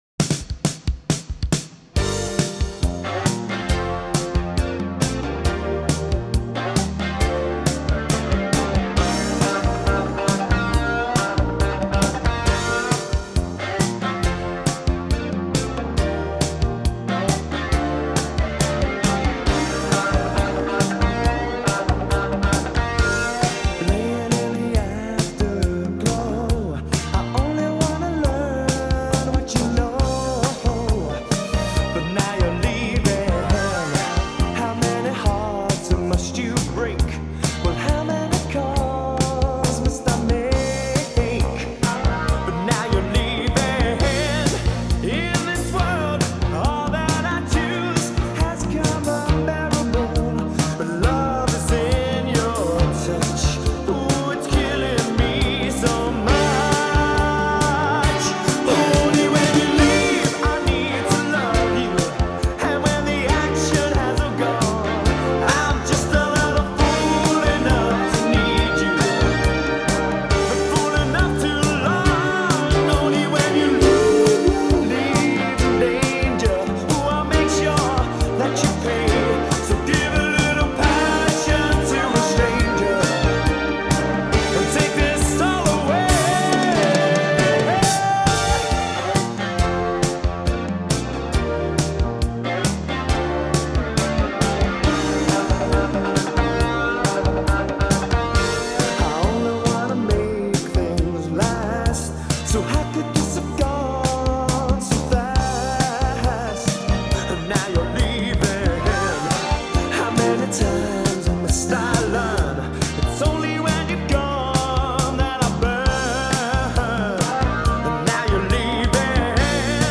Románticos y nostálgicos